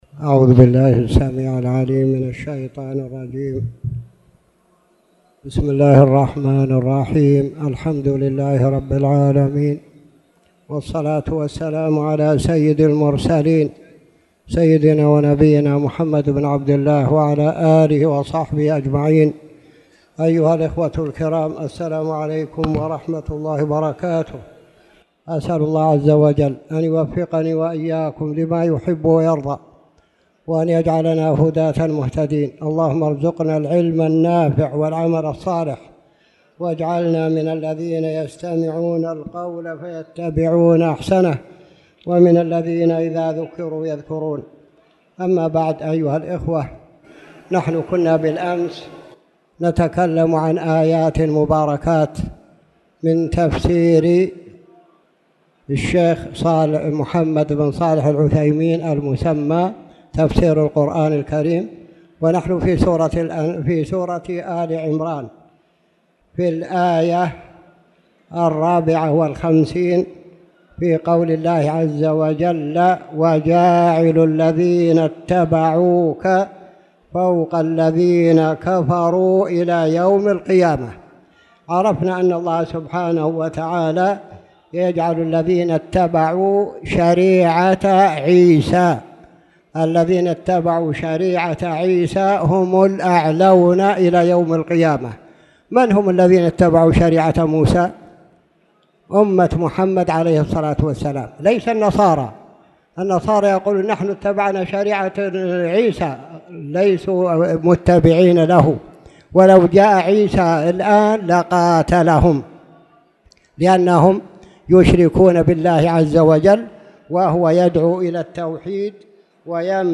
تاريخ النشر ٣٠ جمادى الأولى ١٤٣٨ هـ المكان: المسجد الحرام الشيخ